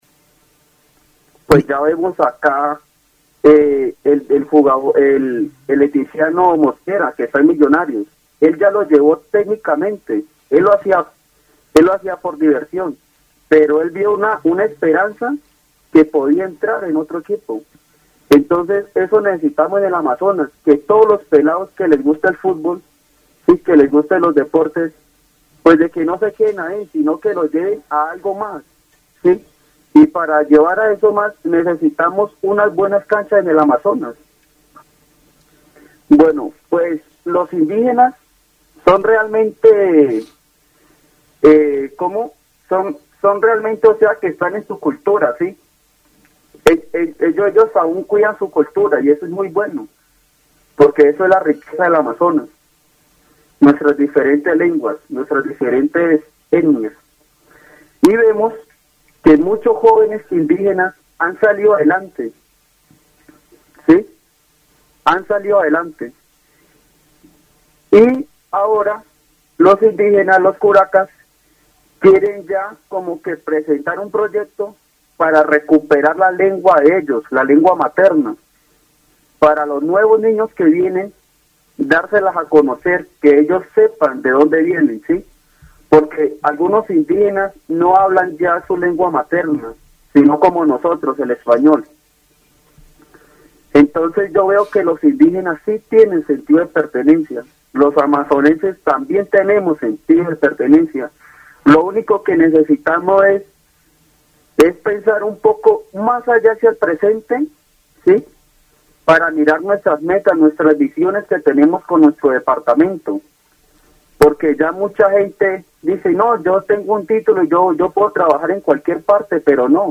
El programa radial presenta una conversación sobre el desarrollo de la juventud en el Amazonas y la importancia de la preservación cultural de los pueblos indígenas. Se destaca el papel del deporte como un medio de motivación y superación para los jóvenes, así como la necesidad de mejorar la educación en la región. Se menciona el esfuerzo de los curacas y líderes indígenas por rescatar las lenguas nativas y transmitirlas a las nuevas generaciones.